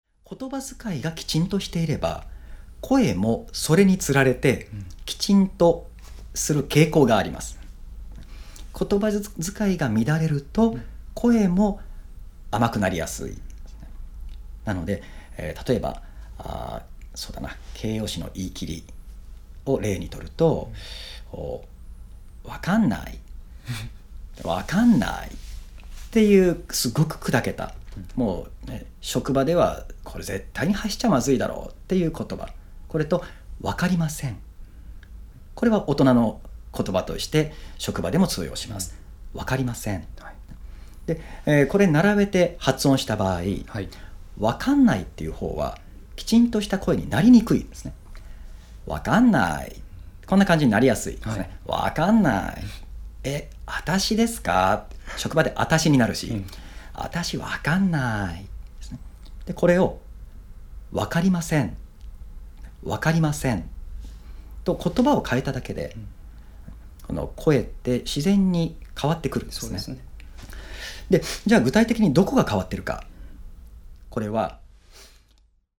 具体的な発声レッスンのところでは、「軟口蓋」を使った発声法をお届けします。
この音声講座は上記の「カートに入れる」ボタンから個別購入することで聴くことができます。